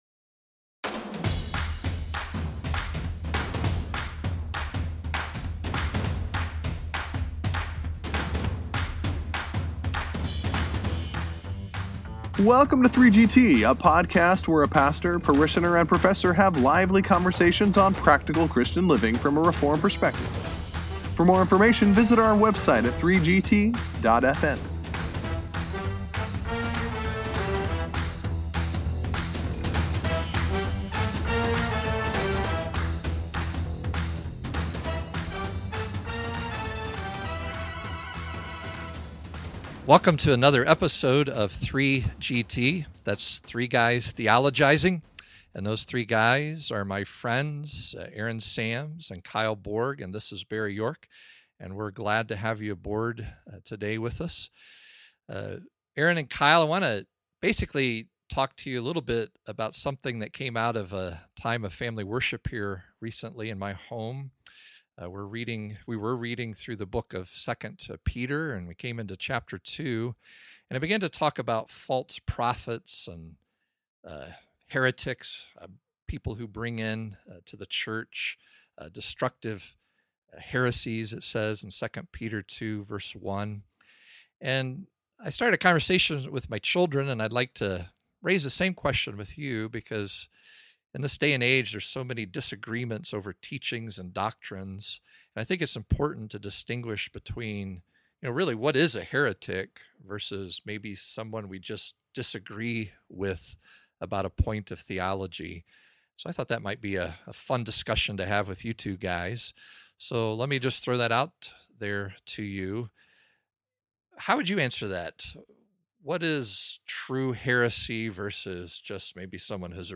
From a question that arose during family worship, the prof turns the pastor and parishioner’s attention to II Peter 2 and asks how to identify a heretic.